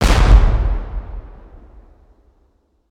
.爆炸.ogg